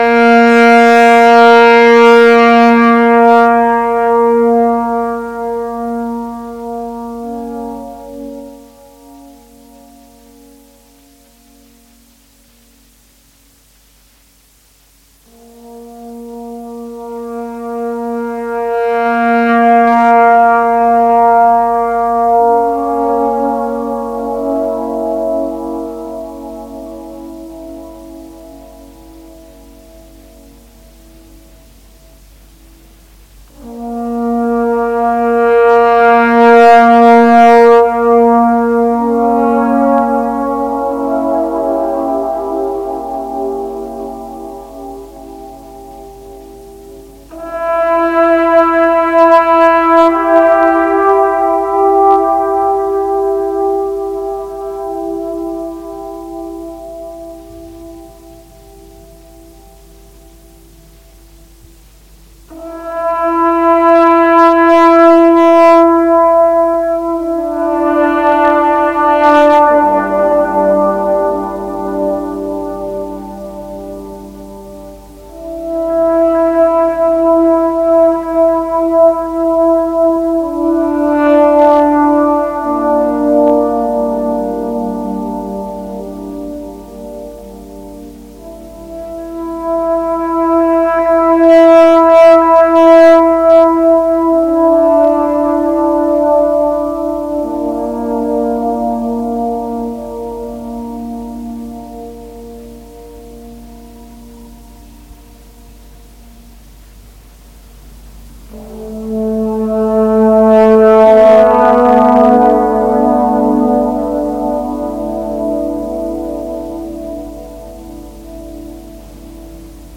Ambient, Early Electronic, Experimental und Industrial